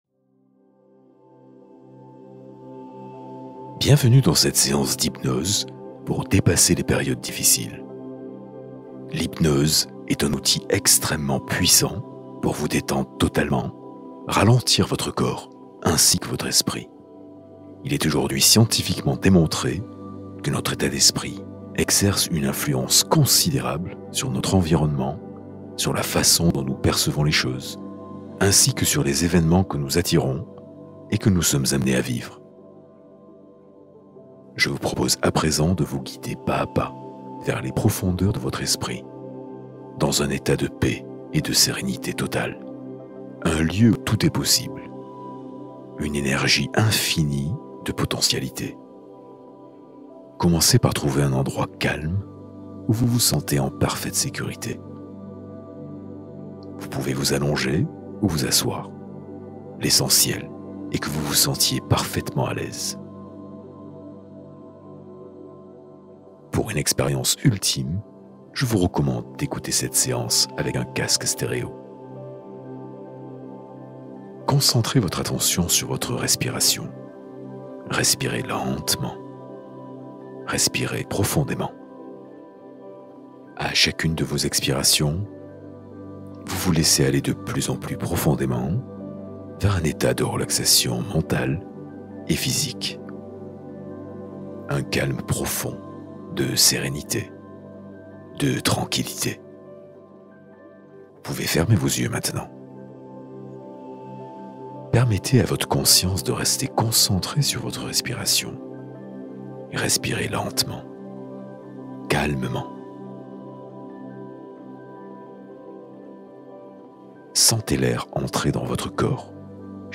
Hypnose puissante : traverser les moments difficiles